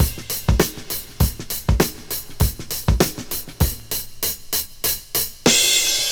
• 78 Bpm '00s Drum Loop C# Key.wav
Free drum loop - kick tuned to the C# note. Loudest frequency: 4608Hz
78-bpm-00s-drum-loop-c-sharp-key-Sl2.wav